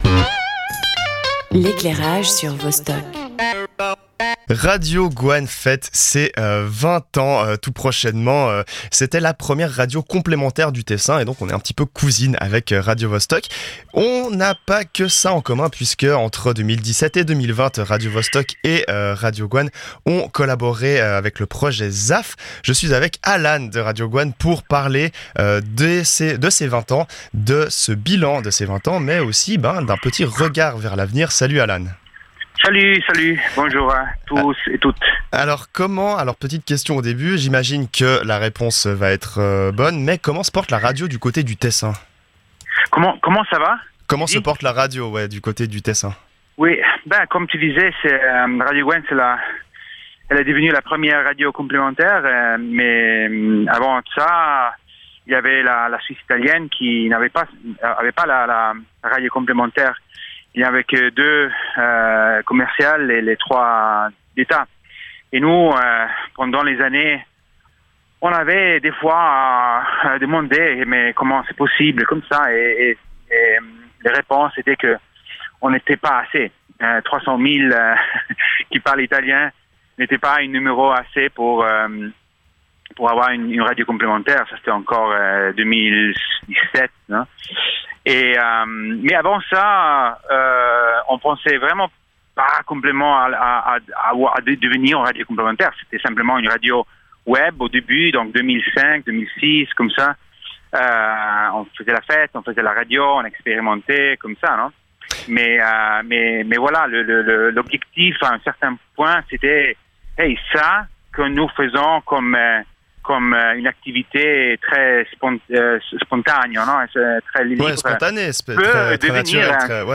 Invité :
Animation :